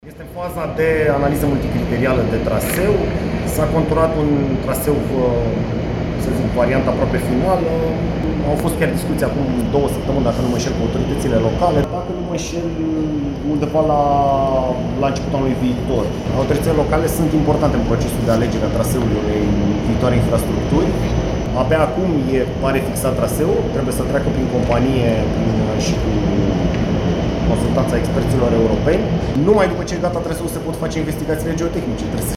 Ministrul Transporturilor, Cătălin Drulă, a anunțat la Arad că proiectul ar putea fi anunţaţat la începutul anului viitor.